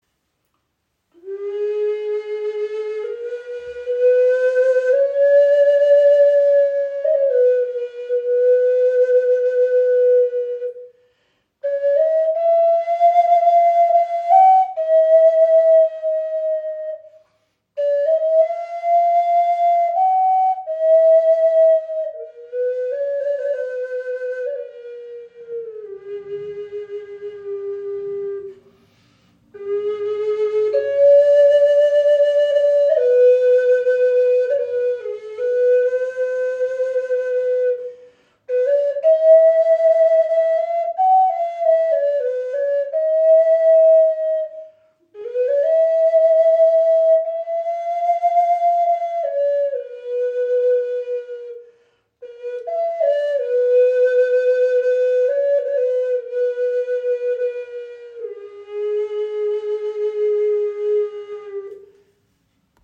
Okarina aus einem Aststück | A4 in 432 Hz | Aeolian Stimmung | ca. 27 cm
Klein, handlich, klangvoll – eine Okarina mit Seele
Handgefertigte 6 Loch Okarina aus Teakast – klarer, warmer Klang in Aeolischer Stimmung in A (432 Hz), jedes Stück ein Unikat.
Die Okarina spricht besonders klar an und erklingt in der Aeolischen Stimmung in A4, fein abgestimmt auf 432 Hz – ein Ton, der Herz und Geist in harmonische Schwingung versetzt.
Trotz ihrer handlichen Grösse erzeugt sie einen angenehm tiefen, warmen Klang – fast ebenbürtig zur nordamerikanischen Gebetsflöte.